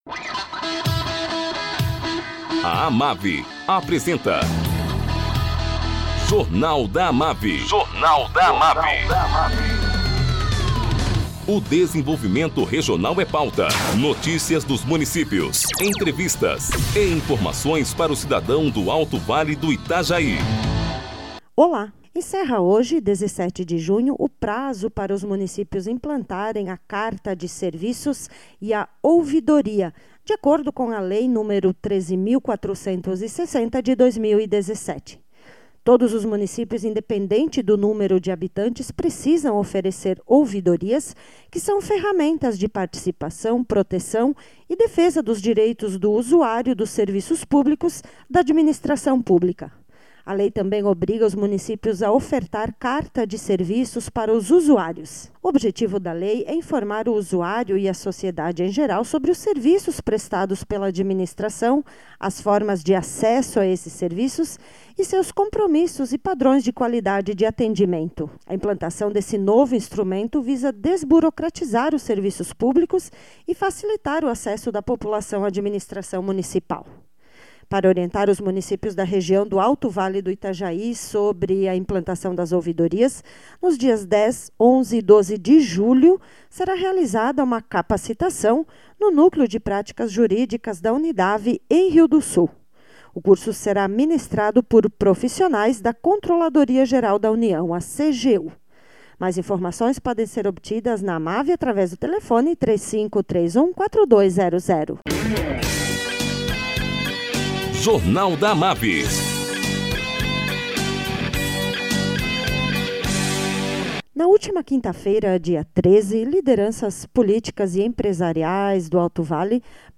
Prefeito de Rio do Sul, José Thomé, fala sobre reunião realizada na última semana com o secretário de Estado da Infraestrutura, Carlos Hassler, para tratar da conclusão da obra de ligação Rio do Sul a President eGetúlio.